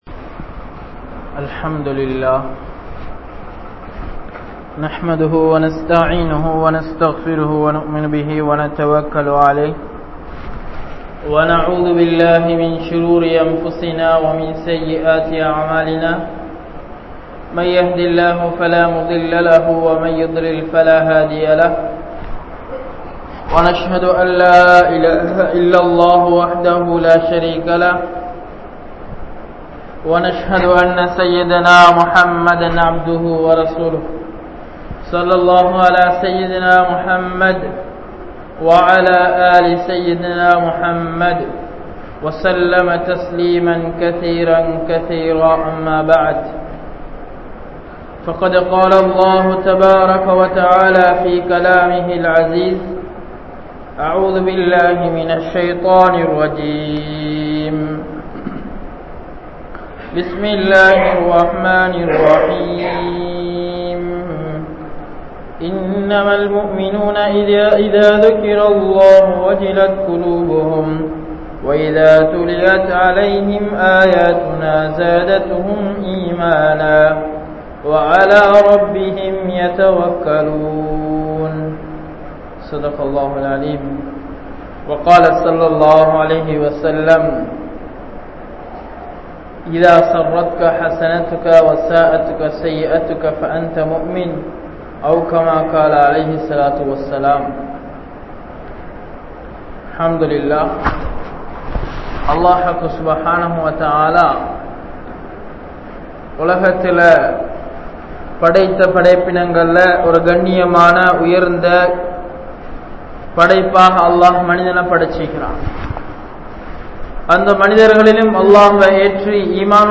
Thaqwa Udaiyavarhalin Panpuhal (தக்வா உடையவர்களின் பண்புகள்) | Audio Bayans | All Ceylon Muslim Youth Community | Addalaichenai
Masjithur Ravaha